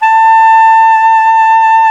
SAX TENORB1I.wav